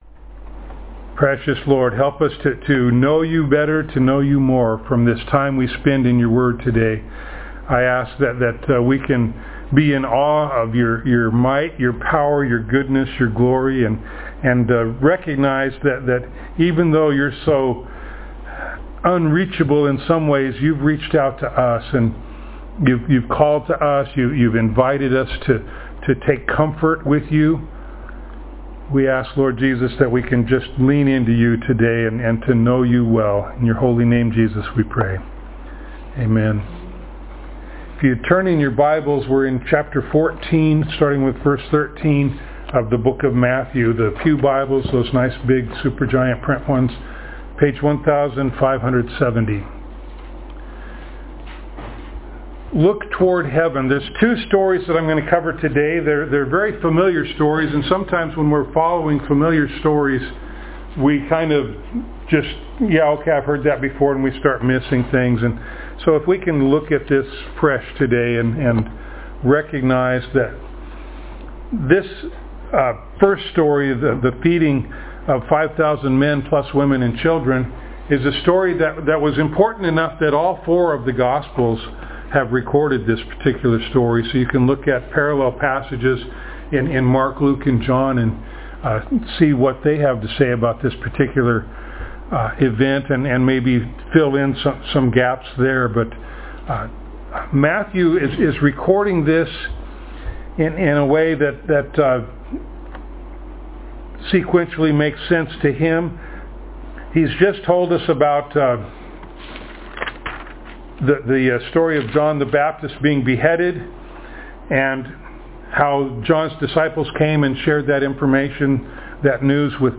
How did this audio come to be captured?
Matthew Passage: Matthew 14:13-36 Service Type: Sunday Morning Download Files Notes « Fear of Man Traditions